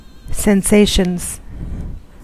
Ääntäminen
Ääntäminen US Haettu sana löytyi näillä lähdekielillä: englanti Käännöksiä ei löytynyt valitulle kohdekielelle. Sensations on sanan sensation monikko.